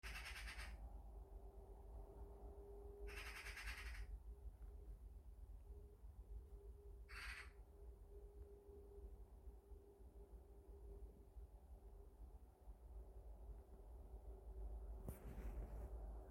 Birds -> Crows ->
Magpie, Pica pica
StatusVoice, calls heard